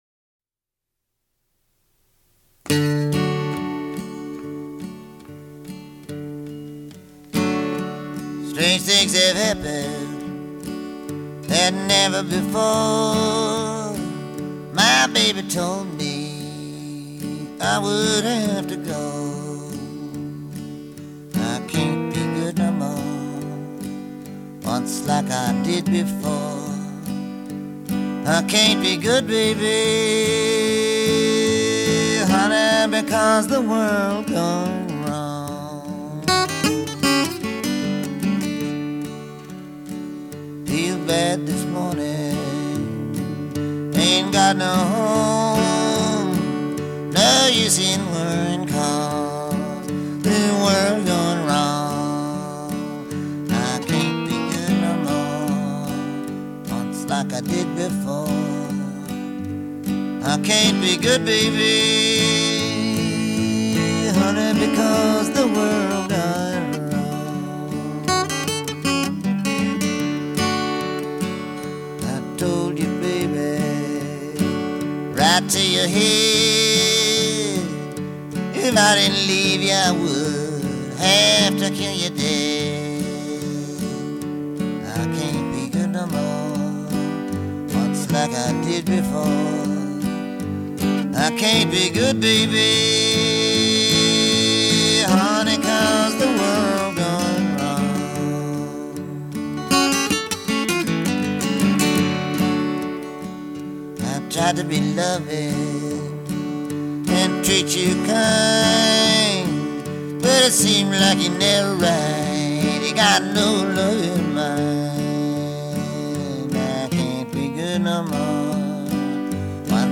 acoustic folk covers